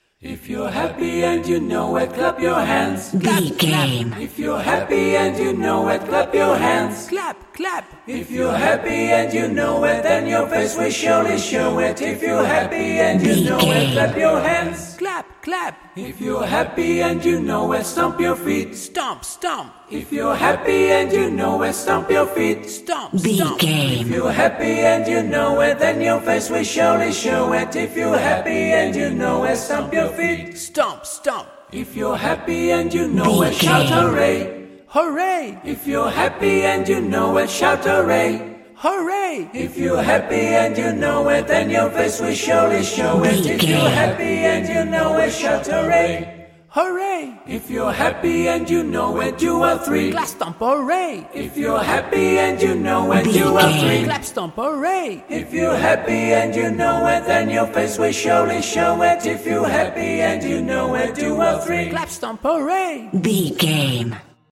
Nursery Rhyme Acapella
royalty free music
Uplifting
Ionian/Major
childlike